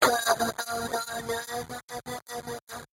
描述：一个带有效果的声乐样本，用我的怪物声音"有我想你，我想你quot。
标签： 120 bpm Weird Loops Vocal Loops 826.92 KB wav Key : Unknown
声道立体声